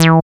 77.07 BASS.wav